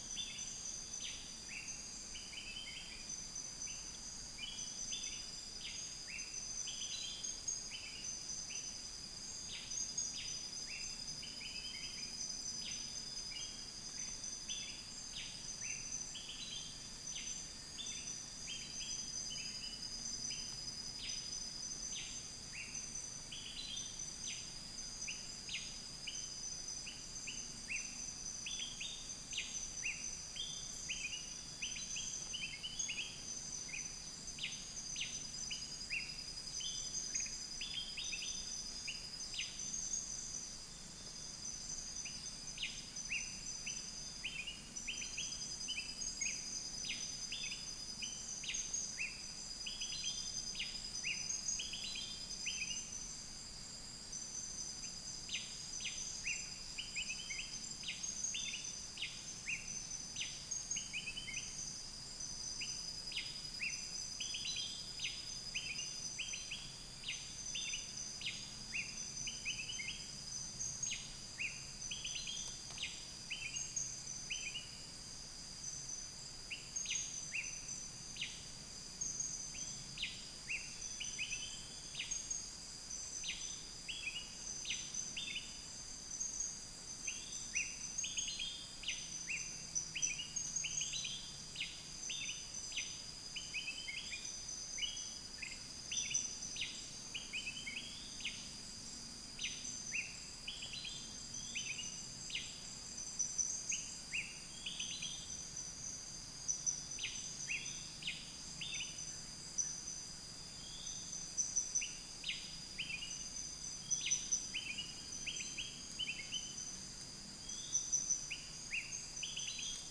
Upland plots dry season 2013
Eurylaimus ochromalus
Malacopteron magnirostre
Chloropsis moluccensis
Pellorneum nigrocapitatum